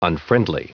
Prononciation du mot unfriendly en anglais (fichier audio)
Prononciation du mot : unfriendly